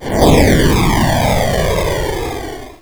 engine.wav